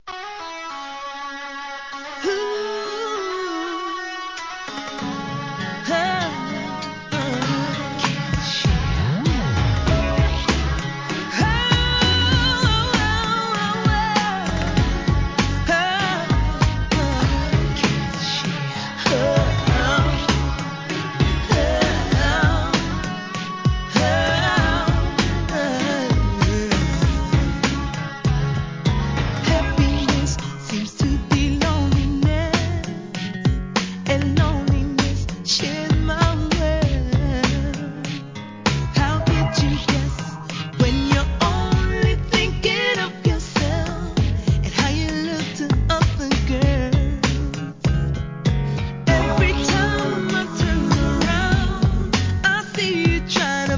HIP HOP/R&B
正統派R&B